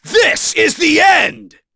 Chase-Vox_Skill4.wav